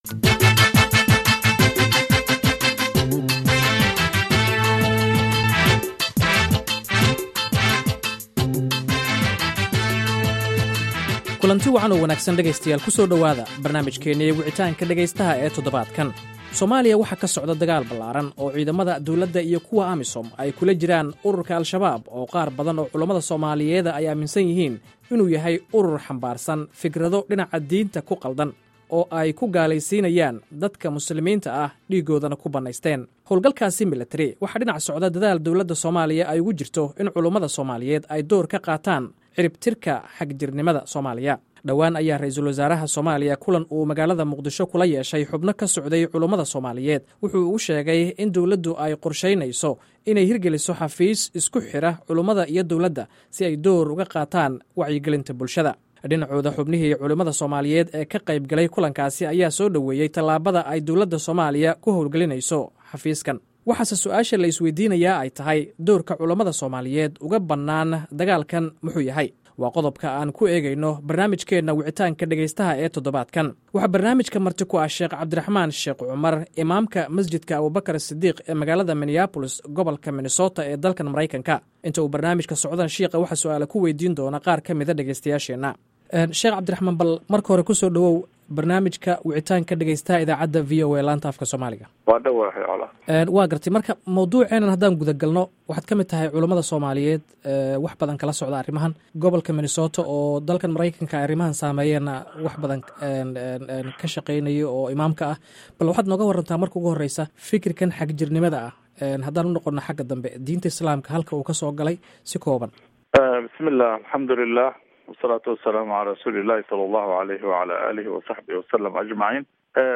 Wicitaanka